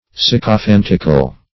Sycophantic \Syc`o*phan"tic\, Sycophantical \Syc`o*phan"tic*al\,